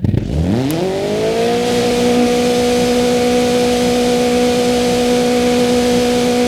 Index of /server/sound/vehicles/lwcars/renault_alpine
rev.wav